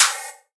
Original creative-commons licensed sounds for DJ's and music producers, recorded with high quality studio microphones.
Open Hat Sample F Key 2.wav
reverb-open-hat-one-shot-f-key-03-9cR.wav